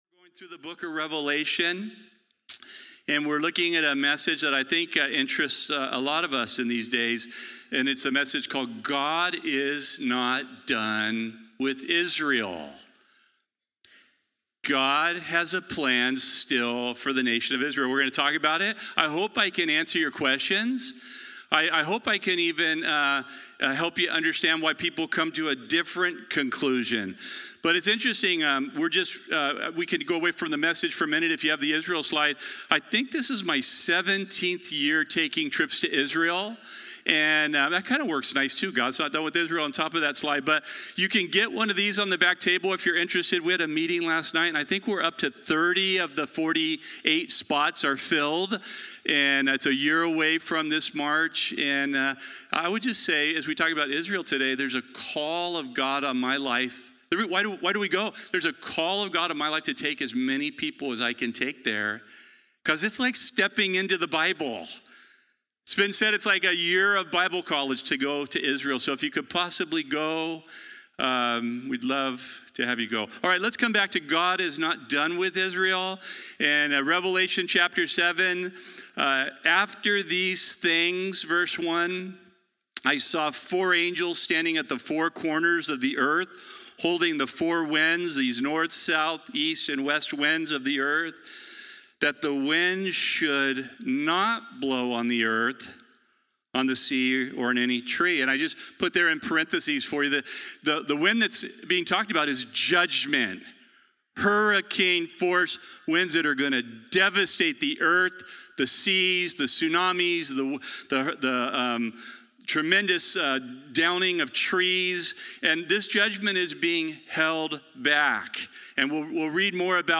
The Bible study given at Calvary Chapel Corvallis on Sunday, February 8, 2026..